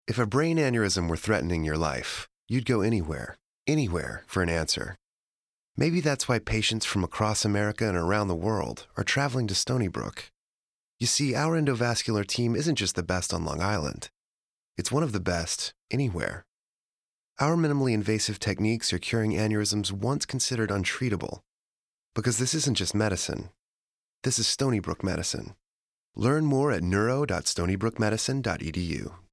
"Anywhere" :30 Radio Spot